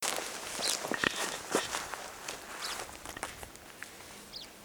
Spornpieper Anthus richardi Richard's Pipit
Simetsberg GAP, 20.11.2012 5 s Rufe eines Ind. auf 1700mNN